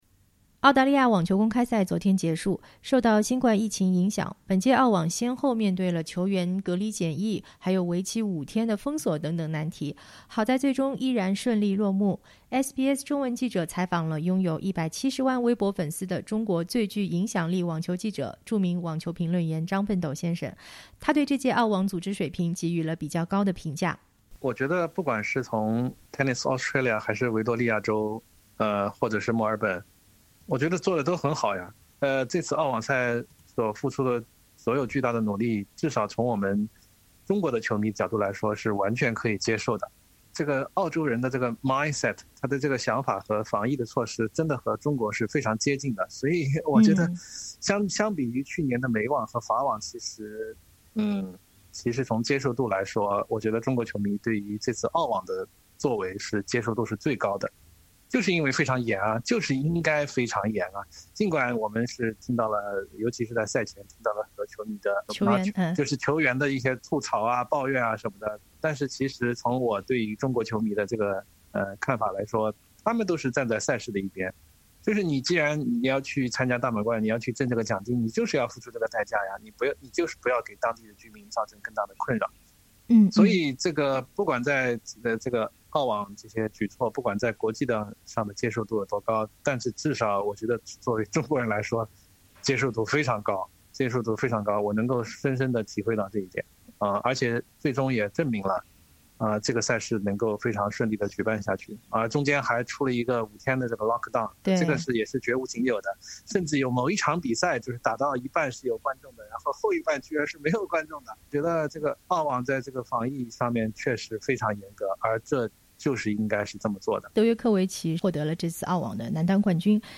SBS中文记者采访了拥有170万微博粉丝的中国最具影响力的网球记者、著名网球评论员张奔斗。